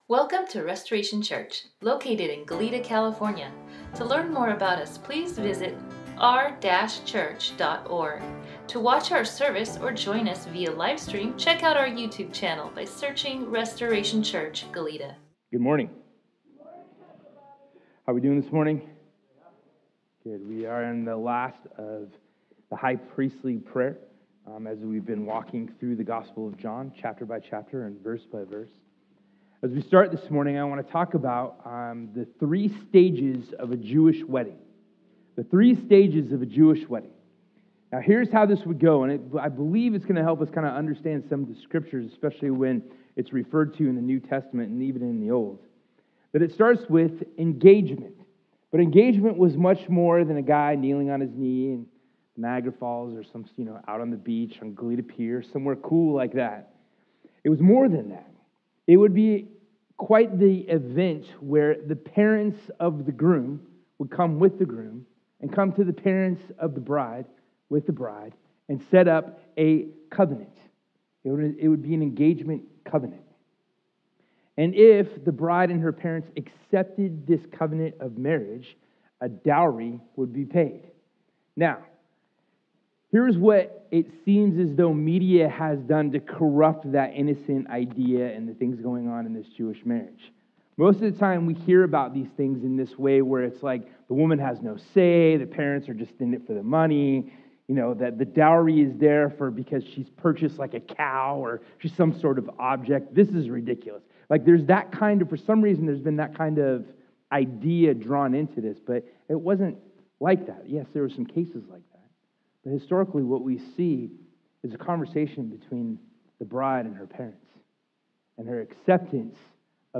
Sermon NotesDownload Service We are stoked that you are checking us out!